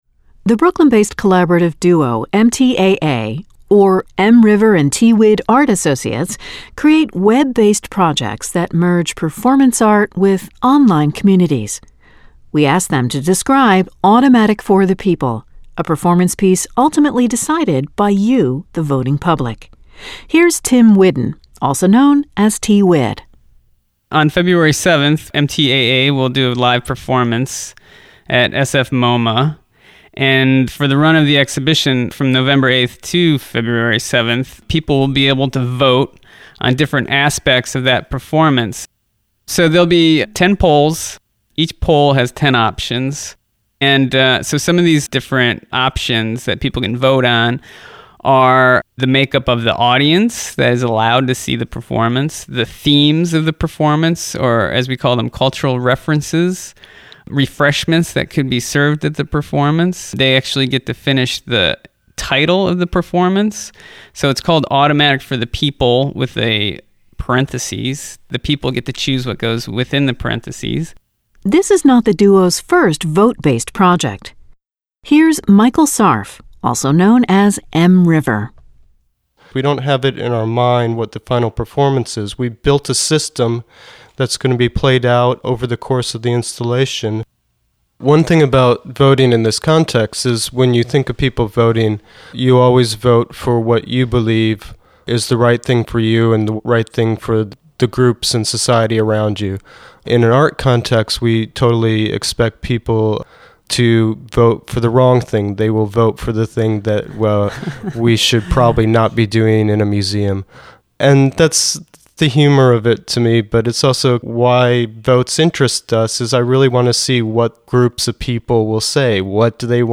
MTAA_sfmoma_audiotour.mp3